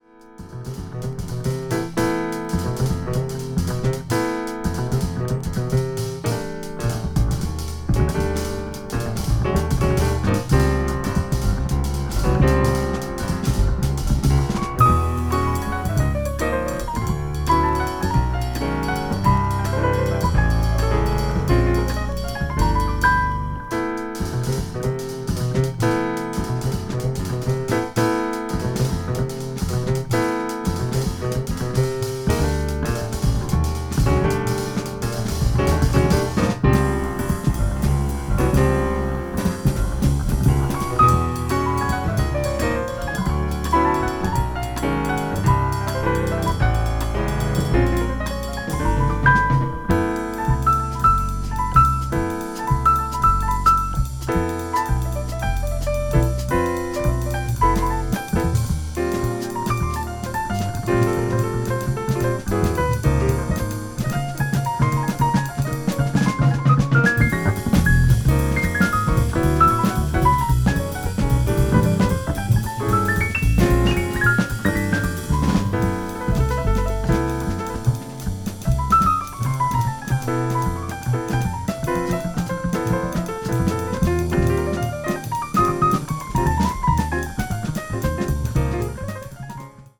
contemporary jazz   deep jazz   post bop   spiritual jazz